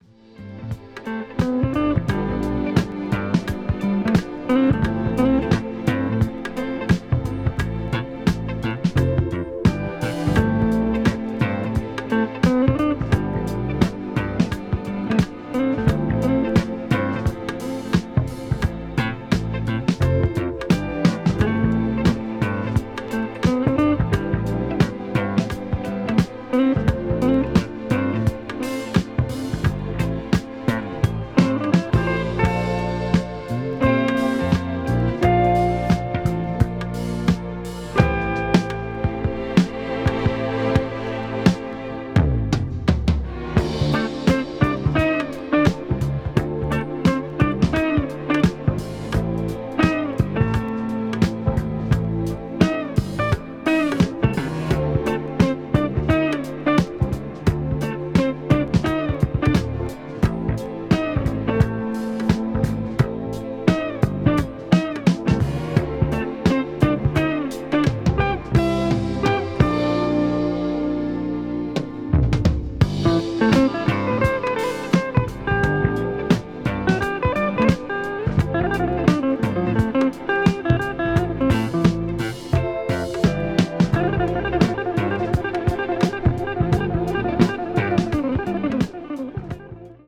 media : EX+/EX+(わずかにチリノイズが入る箇所あり)